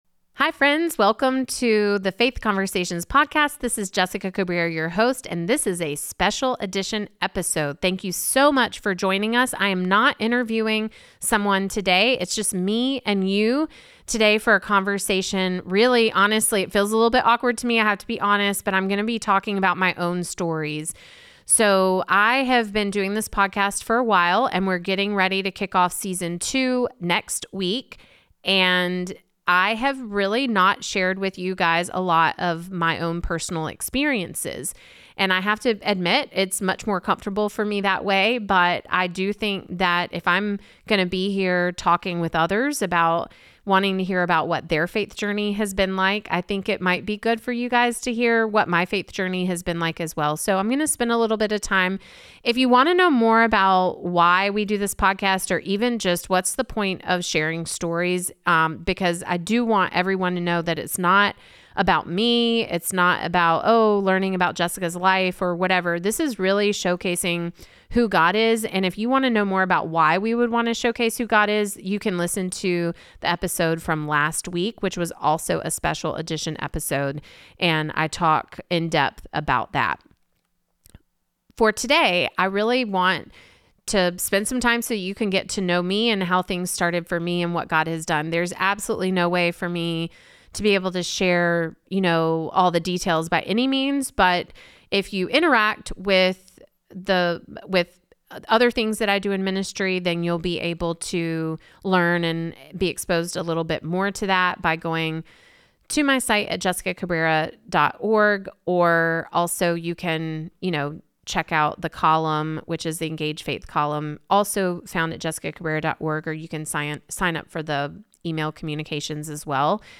In this special solo episode